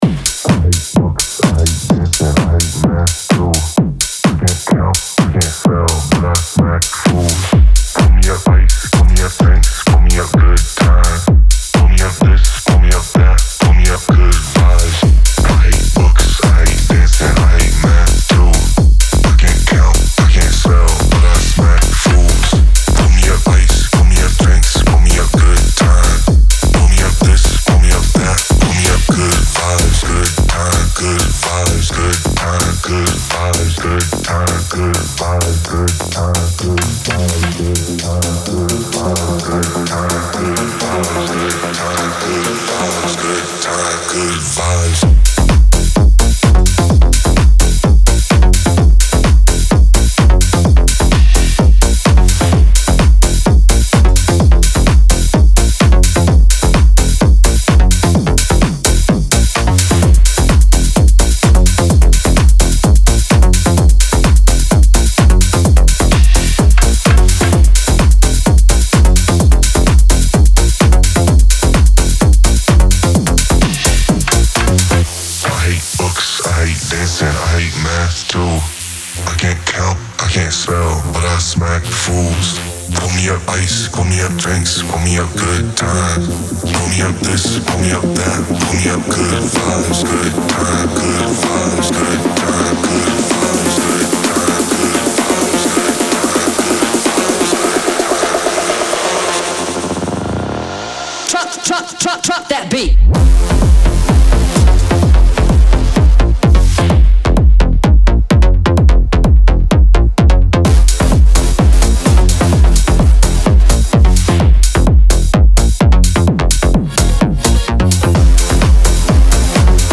• Жанр: Techno, House